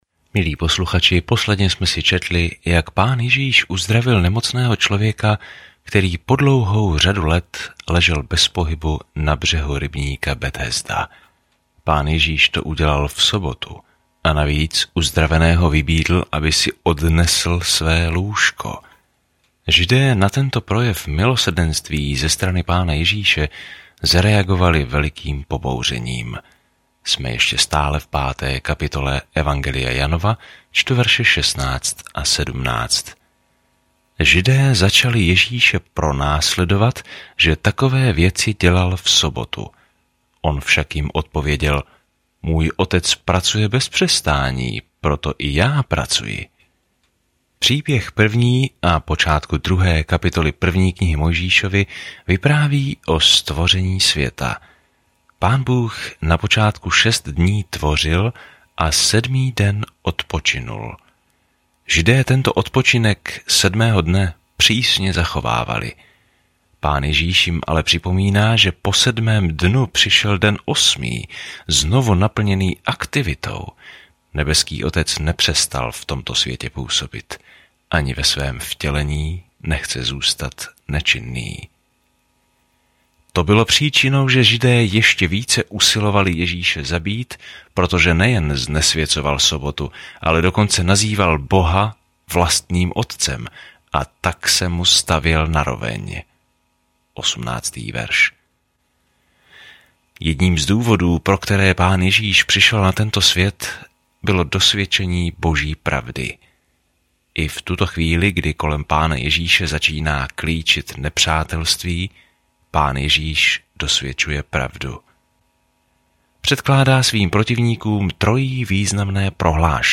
Písmo Jan 5:16-30 Den 10 Začít tento plán Den 12 O tomto plánu Dobrá zpráva, kterou Jan vysvětluje, je jedinečná od ostatních evangelií a zaměřuje se na to, proč bychom měli věřit v Ježíše Krista a jak žít v tomto jménu. Denně procházejte Janem a poslouchejte audiostudii a čtěte vybrané verše z Božího slova.